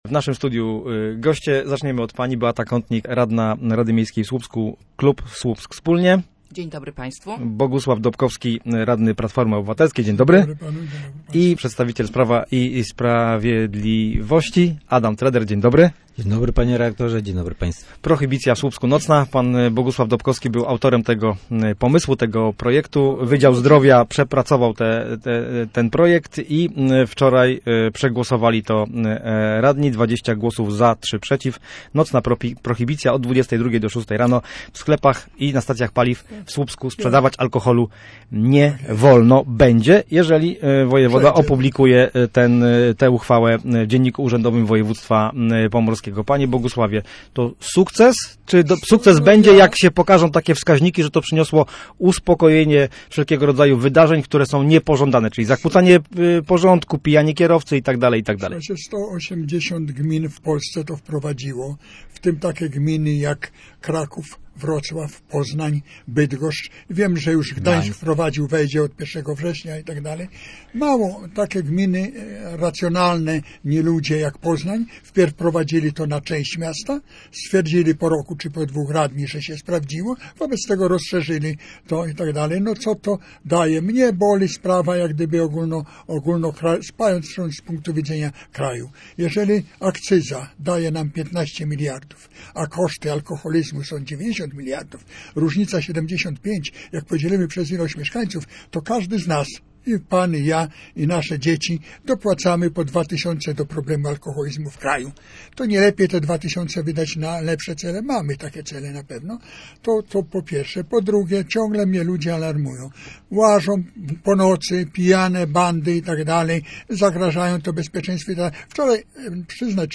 Radni_debata_OK.mp3